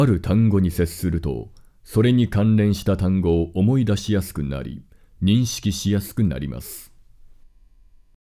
リップノイズは文章の区切りや息継ぎのタイミングで発生しやすいですね。
音声の方も聞き比べるとチャ、パッというリップノイズが低減されているのが分かります（ノイズ注意）